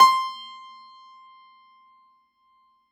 53f-pno18-C4.aif